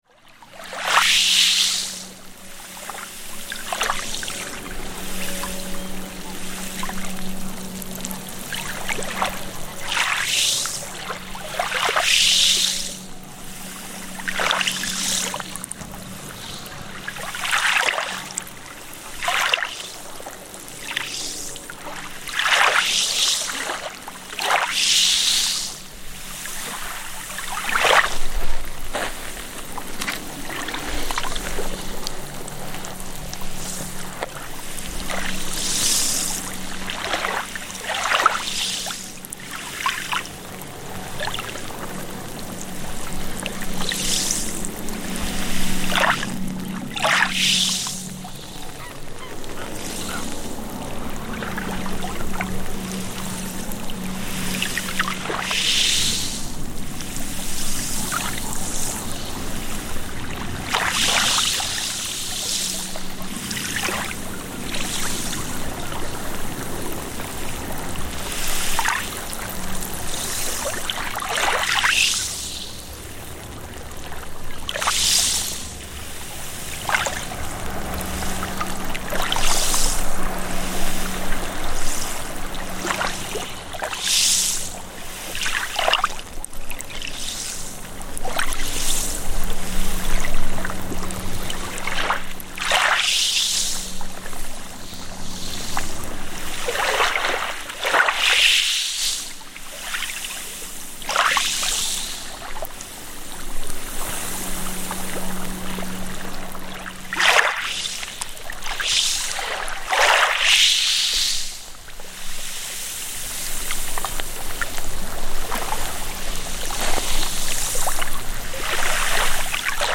Fascinating micro-sounds from Coral Beach, when tiny, slow and quiet waves on this unique beach made of tiny stone, coral and shell fragments creates a beautiful "whooshing" sound with every pass.
Recorded on Coral Beach on the Isle of Skye by Cities and Memory, April 2025.